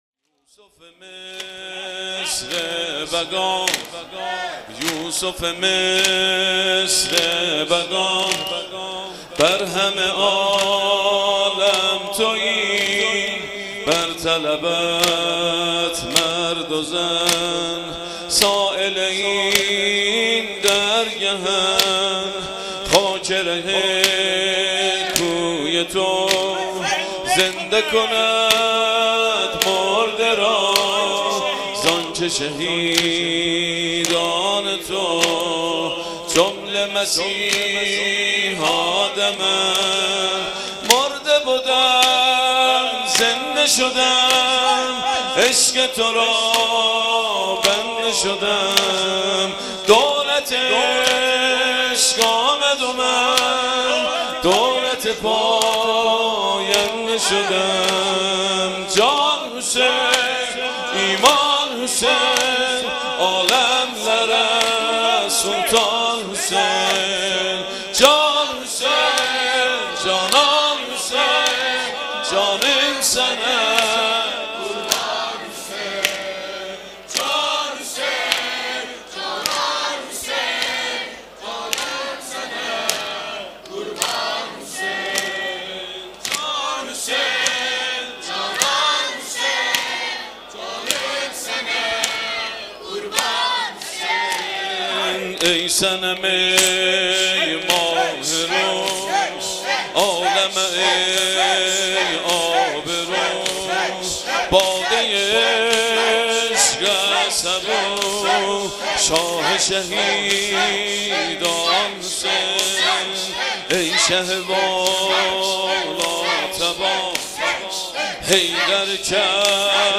هیأت الجواد (رهروان امام و شهدا)